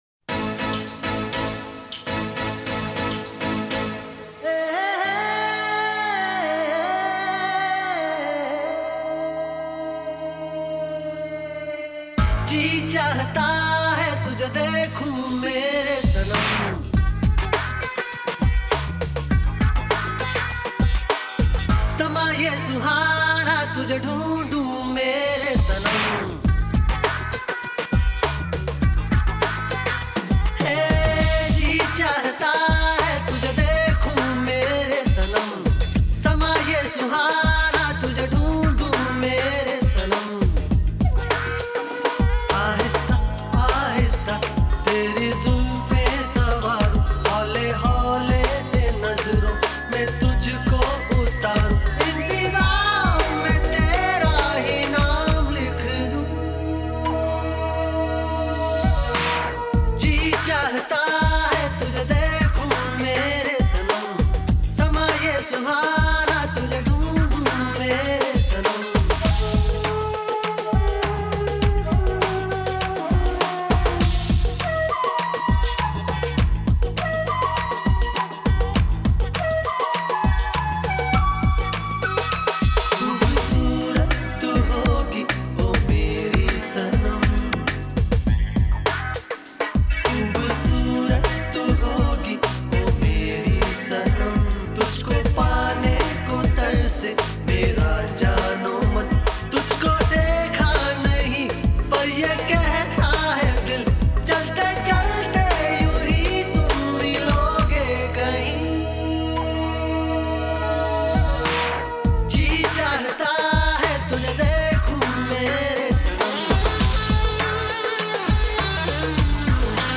2. INDIPOP MP3 Songs